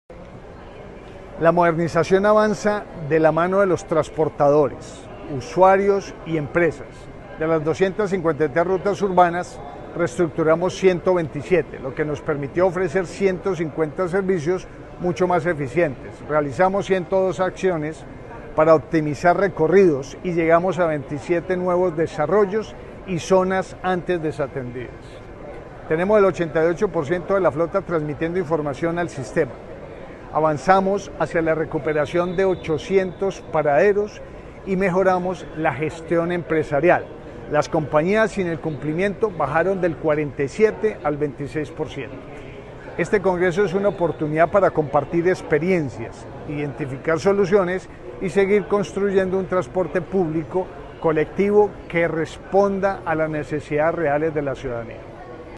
Declaraciones del secretario de Movilidad, Pablo Ruiz
Declaraciones-del-secretario-de-Movilidad-Pablo-Ruiz-1.mp3